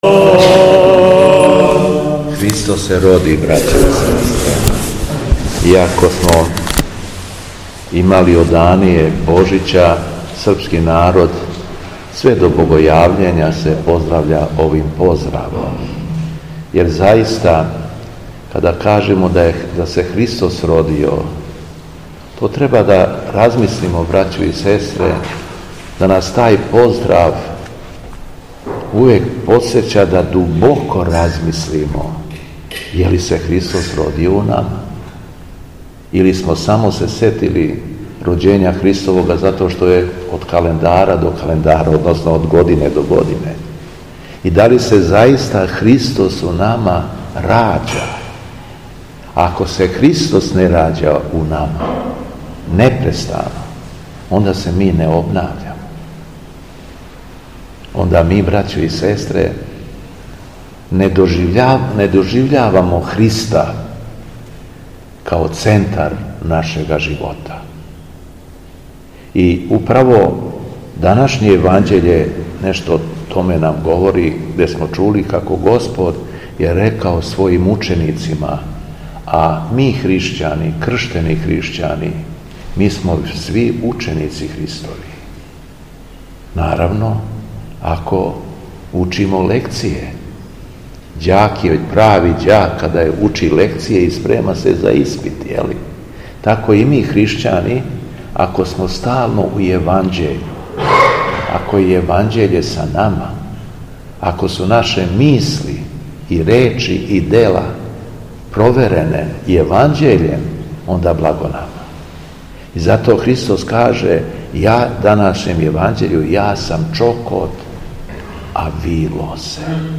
СВЕТА ЕВХАРИСТИЈА У ЦРКВИ СВЕТОГ ЈОАНИКИЈА ДЕВИЧКОГ У БРЕСНИЦИ - Епархија Шумадијска
Беседа Његовог Високопреосвештенства Митрополита шумадијског г. Јована